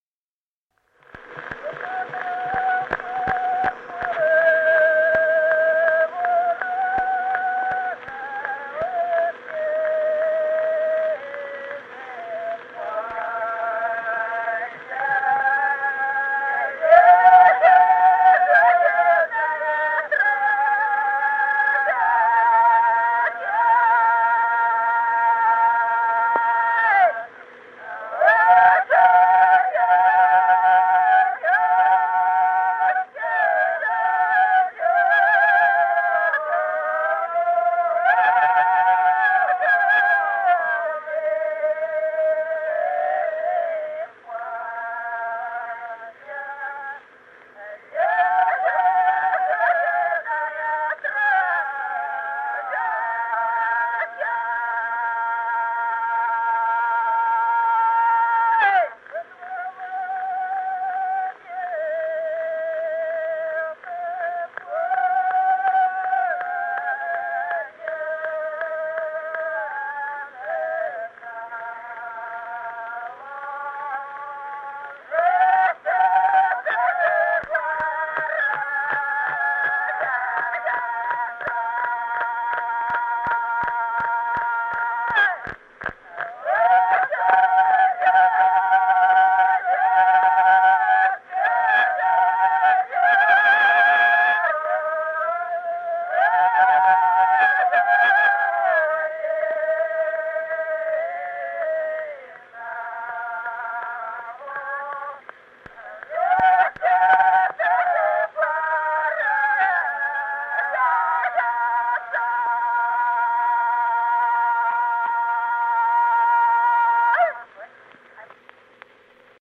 Песни села Остроглядово. Что по травке, по муравке.